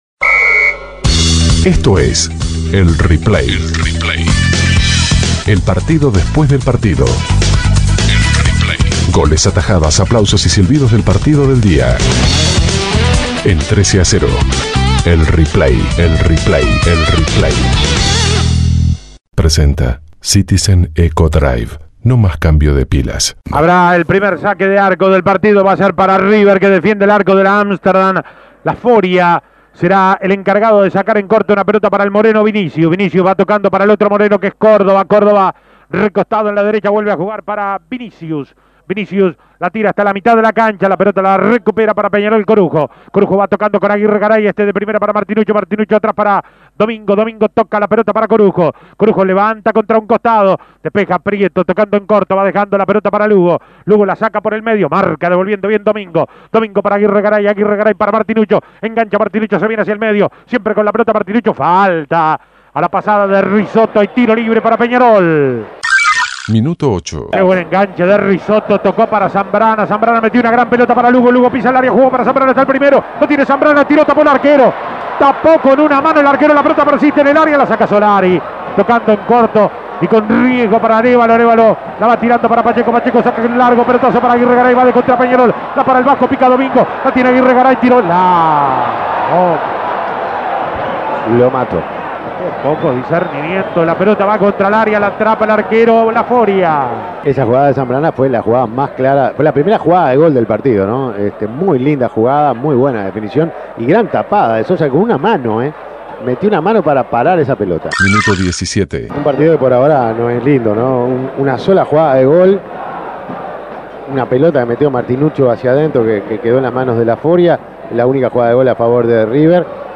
Goles y comentarios Escuche el replay de River - Peñarol Imprimir A- A A+ River Plate venció 2 a 1 por la séptima fecha del torneo Apertura 2010.